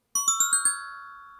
Gliding_warbler.ogg